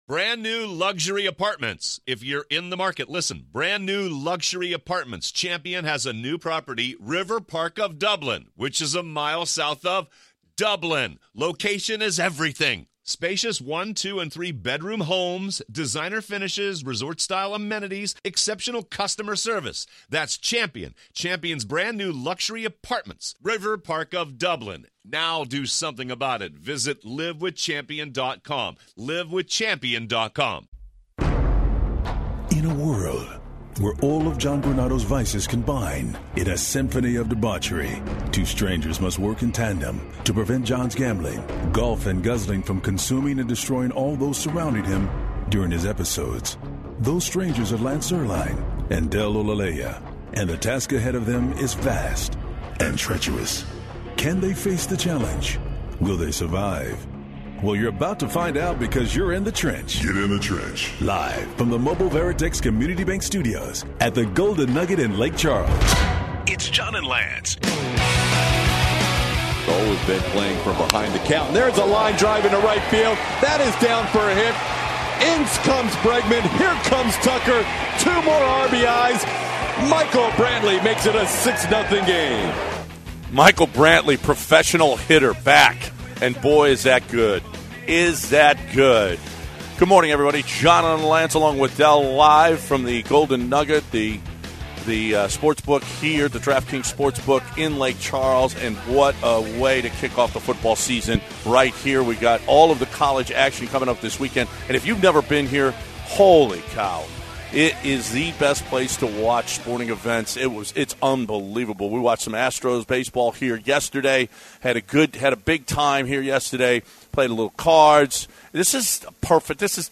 LIVE from the Golden Nugget Casino in Lake Charles!